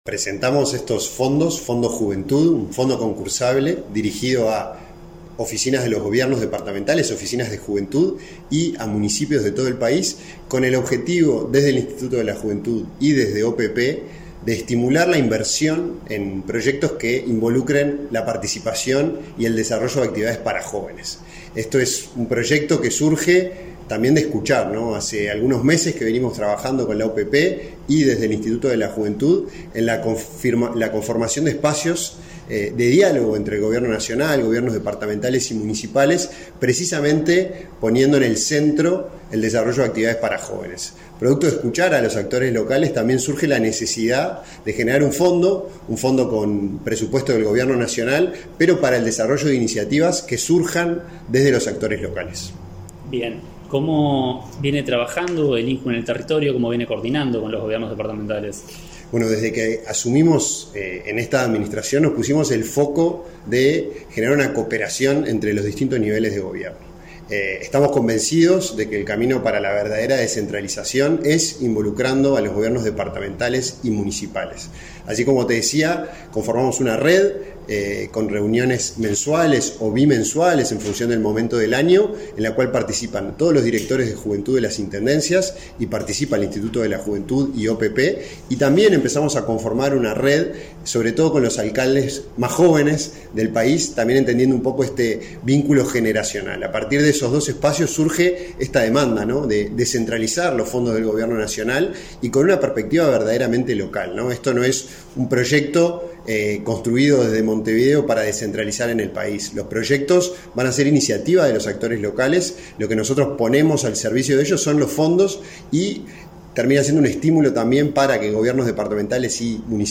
Entrevista al director del INJU, Felipe Paullier
La Oficina de Planeamiento y Presupuesto (OPP) y el Instituto Nacional de la Juventud (INJU) lanzaron, este 7 de noviembre, un llamado a iniciativas dirigida a las Oficinas de la Juventud de los Gobiernos departamentales, y a los 125 municipios del país. Tras el evento, el director del INJU, Felipe Paullier, realizó declaraciones a Comunicación Presidencial.